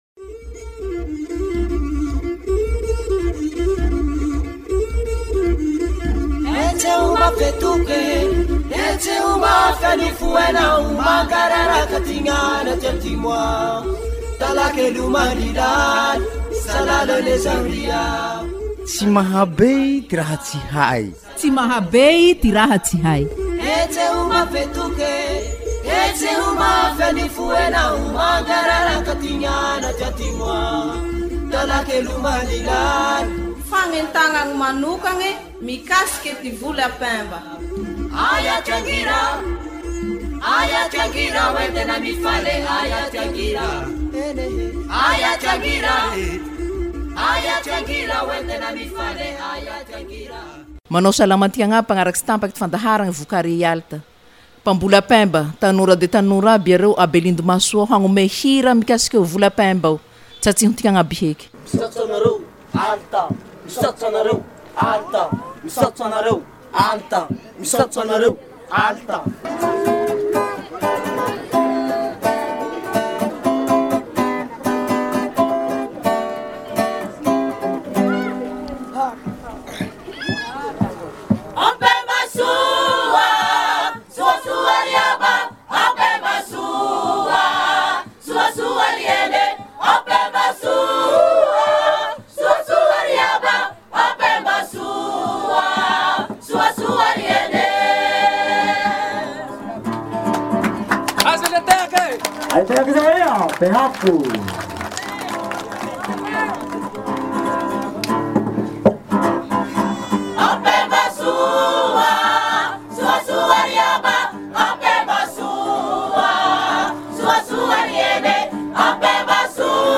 Chanson fermiers belindo festival de sorgho à Belindo
chanson_fermiers_belindo_festival_de_sorgho_a_Belindo.mp3